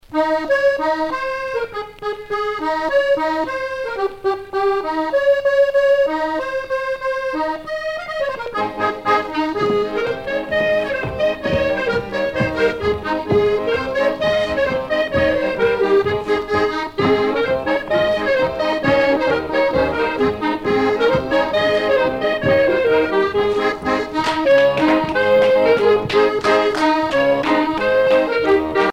danse : polka des bébés ou badoise
Pièce musicale éditée